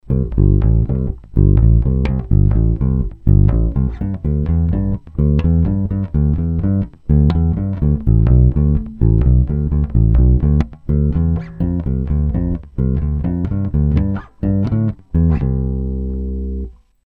Mizerný nahrávky spíchnutý za pár minut.. Tempo nic moc, přeznívání atd. Není to pořádný A/B porovnání - ani dvě z nich nemaj stejný struny, 3*flat, 1*round..
olše/brazilskej palisandr/krkovej snímač/bez tónovky